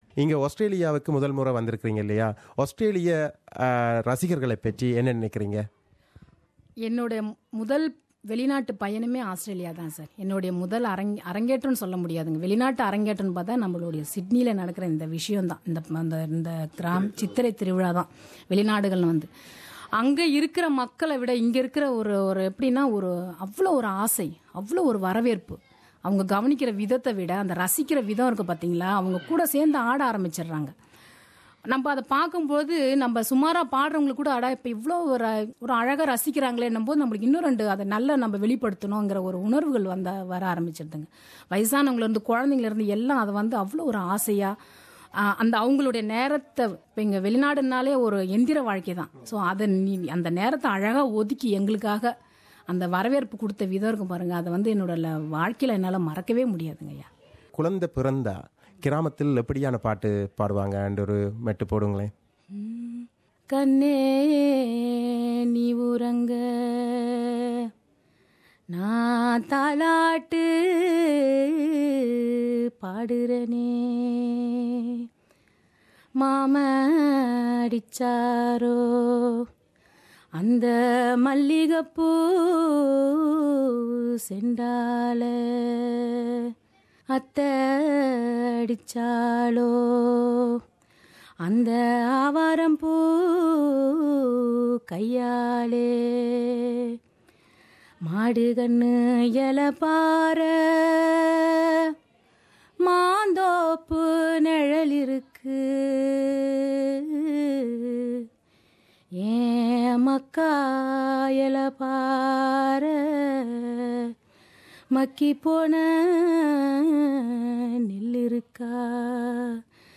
தன் இனிய குரலால் நாட்டுப் புறப் பாடல்களைப் பாடியும்